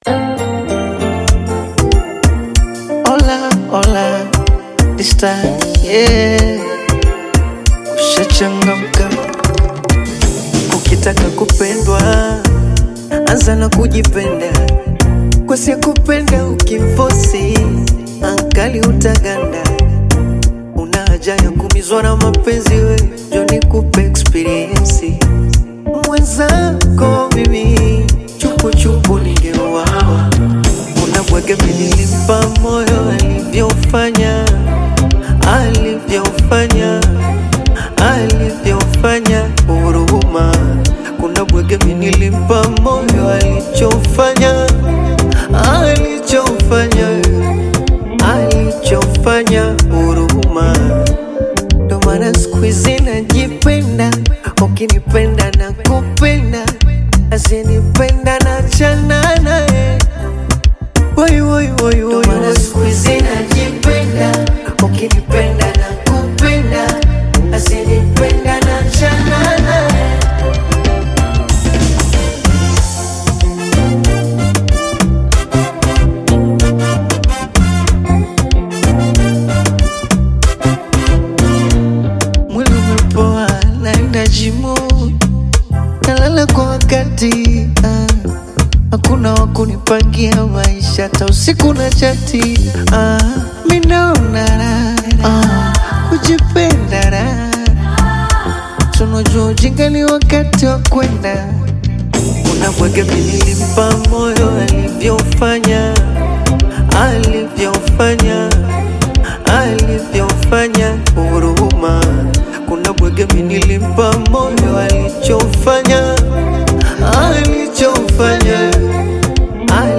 Through honest lyrics and a smooth melody